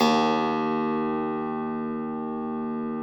53k-pno03-D0.wav